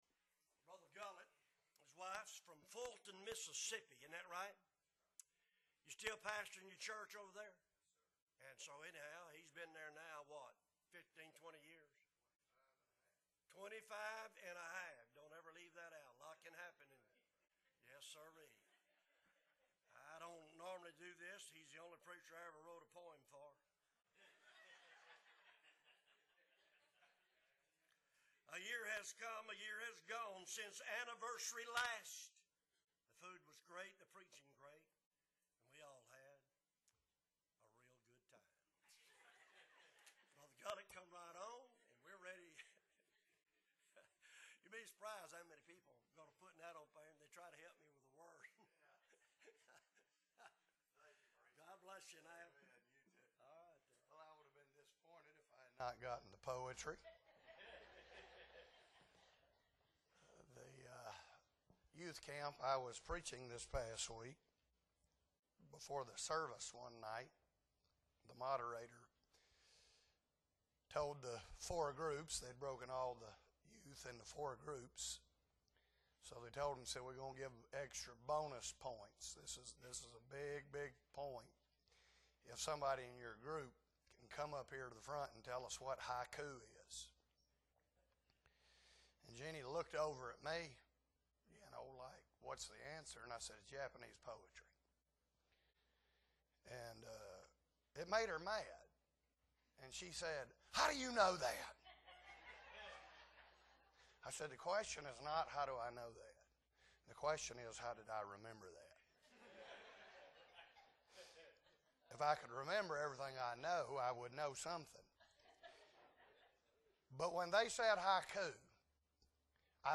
June 23, 2023 Friday Revival Meeting - Appleby Baptist Church
Sermons